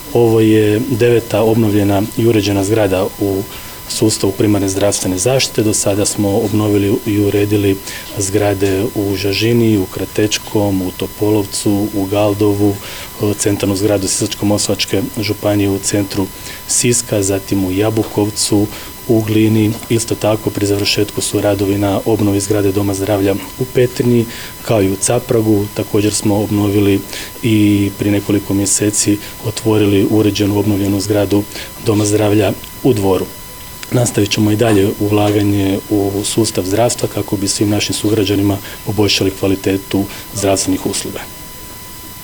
Župan Celjak podsjeća i na ulaganja u obnovu drugih zgrada domova zdravlja